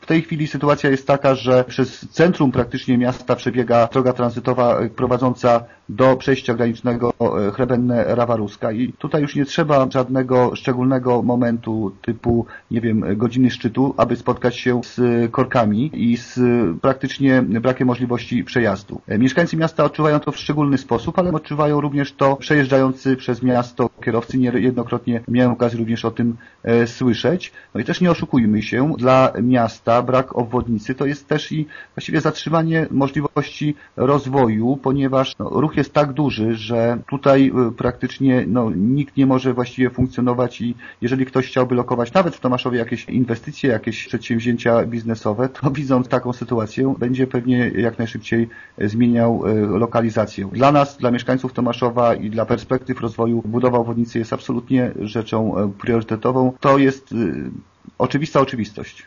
Burmistrz Żukowski podkreśla, że budowa obwodnicy Tomaszowa to priorytetowa inwestycja nie tylko dla mieszkańców miasta i powiatu, ale tez dla całego województwa i wszystkich podróżnych, którzy drogą krajową nr 17 dojeżdżają do granicy polsko-ukraińskiej: